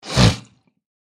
Звуки шмыганья носом
Звук забитого носа при насморке